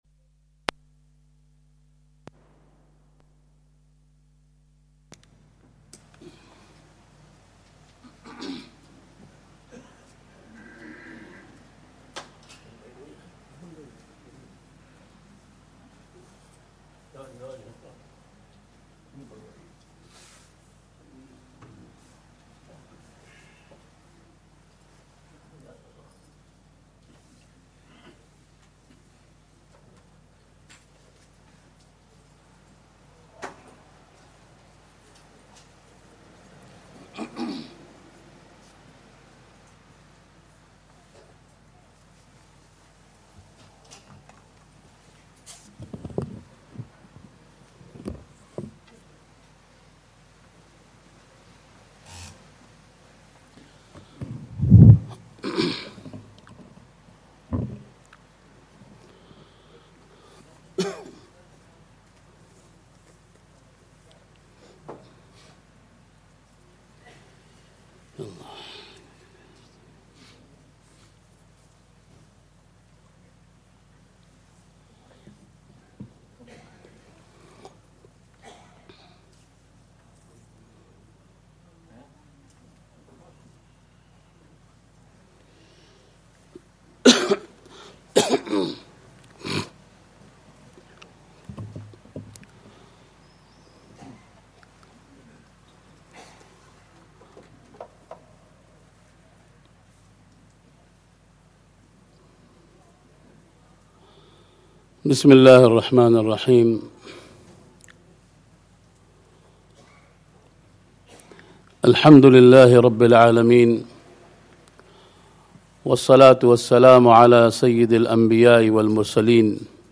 Quwwat-ul-Islam Masjid, Forest Gate, London